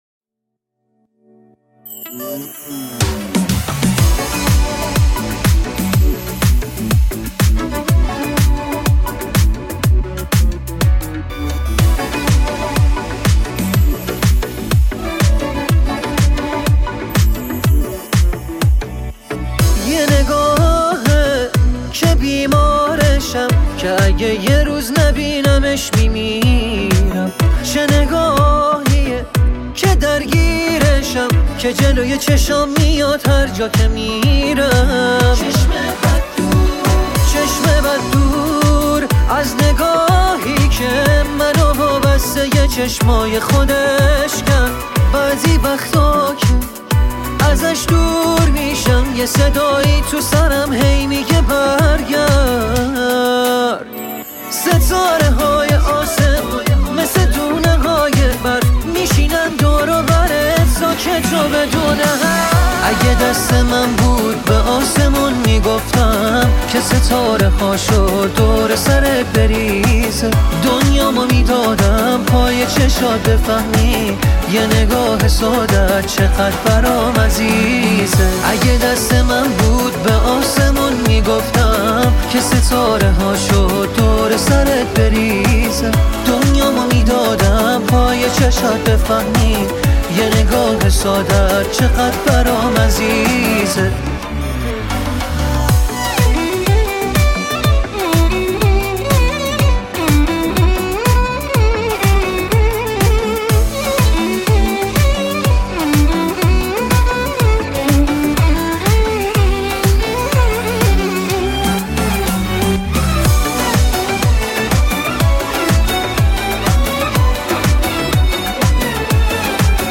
• پاپ
دسته : پاپ